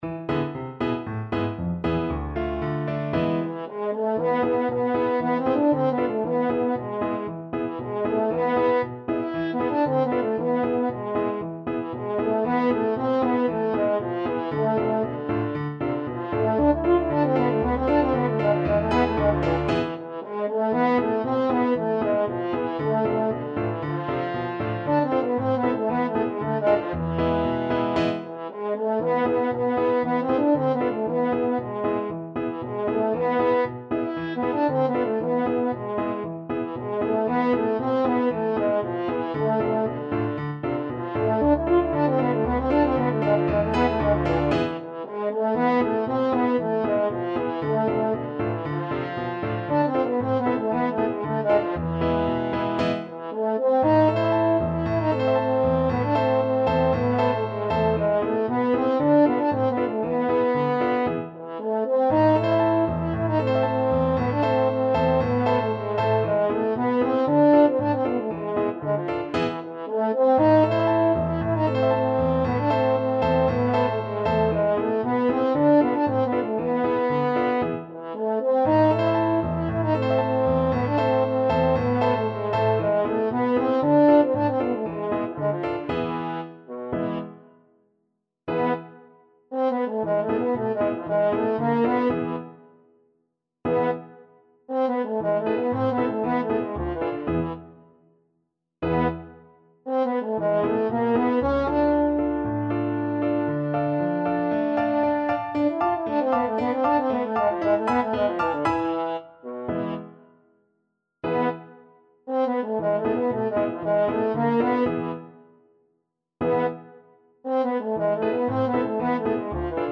French Horn
A minor (Sounding Pitch) E minor (French Horn in F) (View more A minor Music for French Horn )
Allegro =c.116 (View more music marked Allegro)
2/4 (View more 2/4 Music)
Traditional (View more Traditional French Horn Music)
Romanian